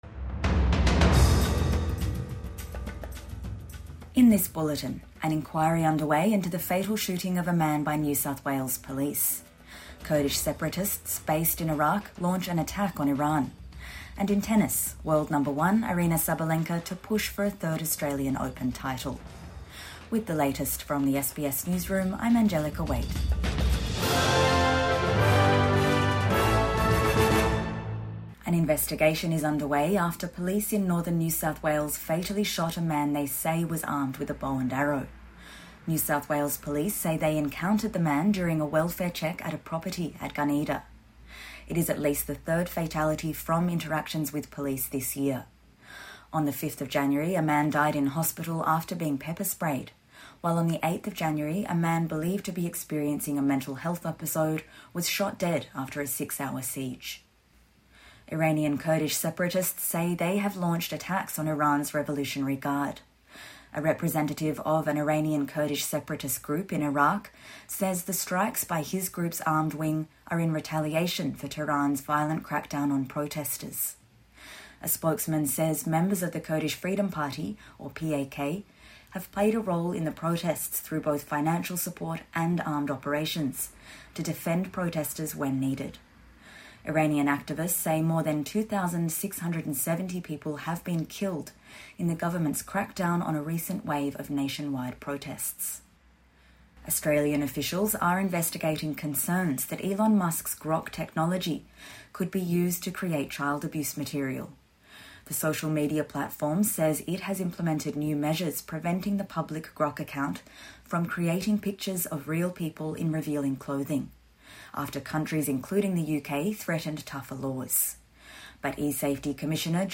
An inquiry underway into fatal shooting of a man by NSW Police | Morning News Bulletin 17 January 2026